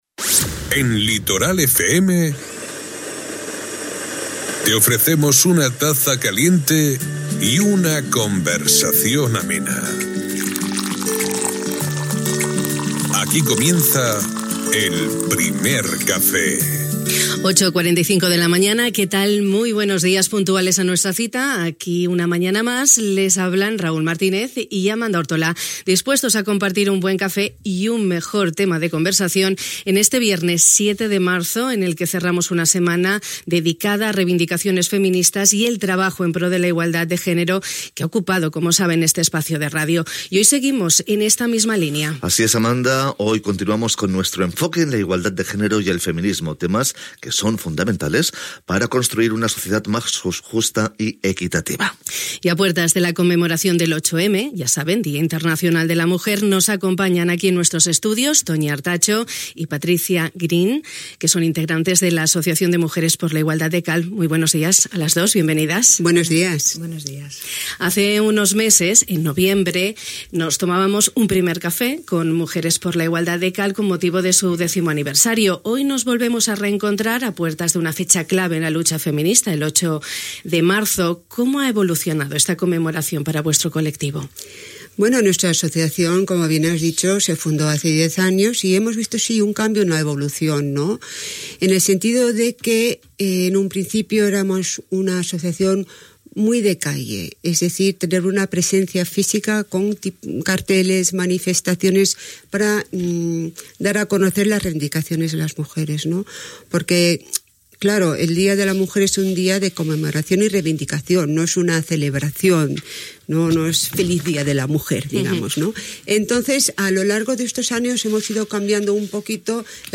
Un colectivo que lleva más de una década en lucha feminista, con un trabajo constante desde distintas vertientes: social, cultural y político. Un trabajo que se prolonga a lo largo de todo el año y que tiene en el 8M, uno de sus momentos clave para alzar la voz y difundir las reivindicaciones feministas, que nuestras invitadas han expuesto a lo largo de la entrevista.